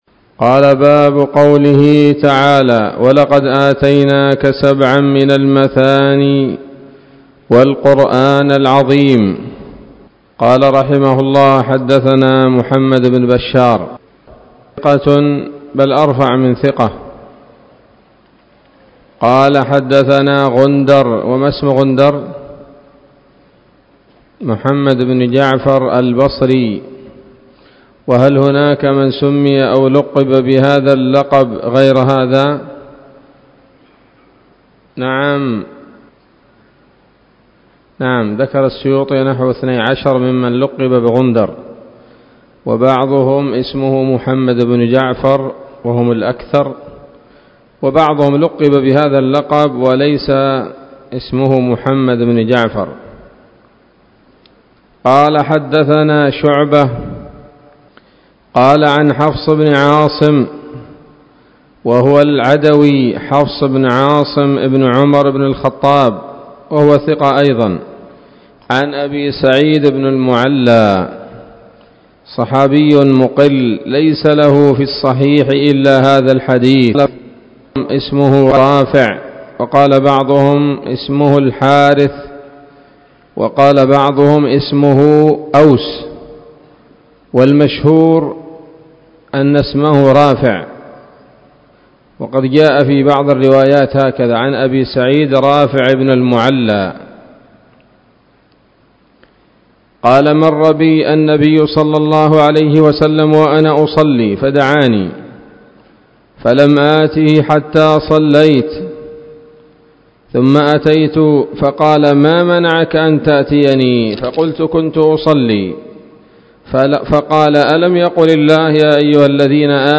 الدرس الثامن والأربعون بعد المائة من كتاب التفسير من صحيح الإمام البخاري